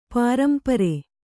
♪ pārampare